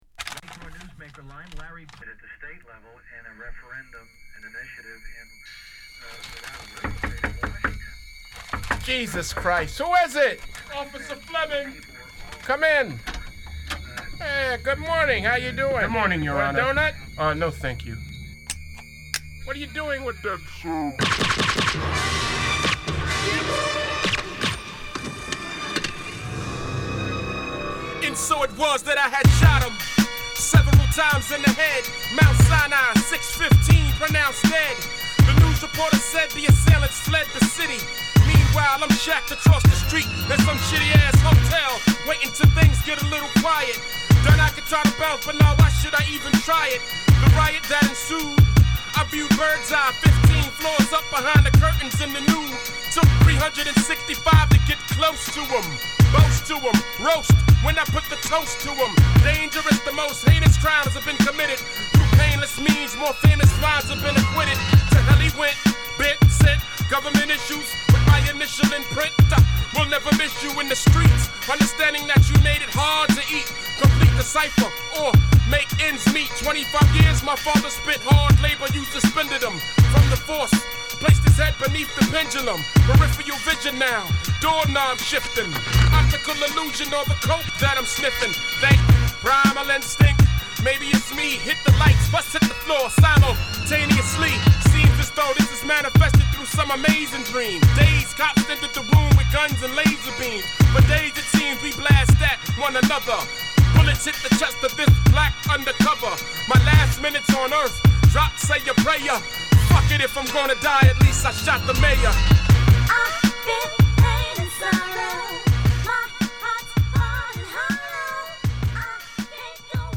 重たいビートに、鋭くスリリングなラップが格好良い